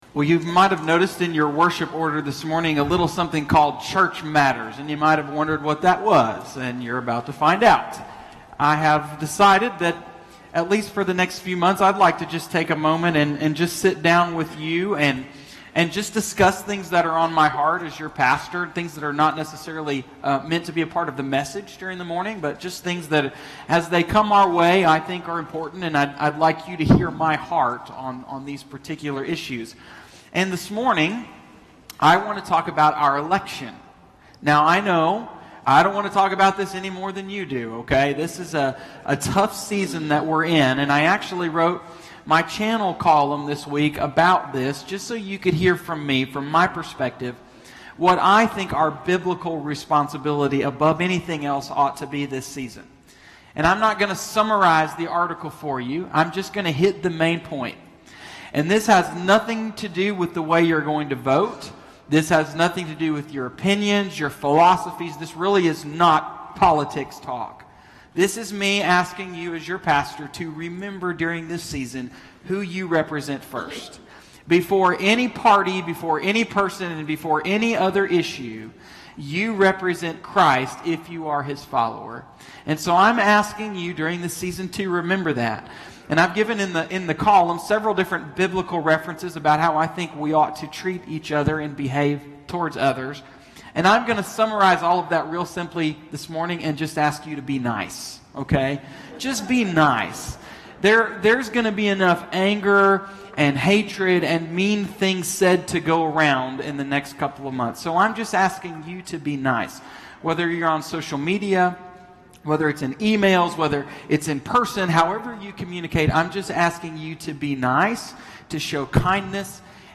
I did this in two pieces – a “sit down” talk with the congregation in both services on Sunday morning, and a written piece in our publication we call The Channel